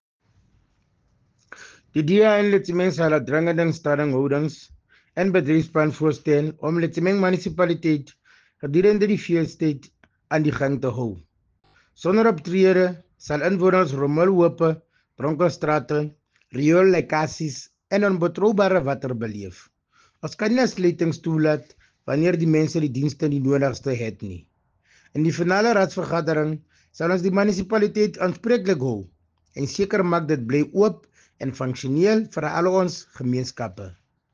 Issued by Cllr. Thabo Nthapo – DA Councillor Letsemeng Municipality
Sesotho soundbites by Cllr Thabo Nthapo.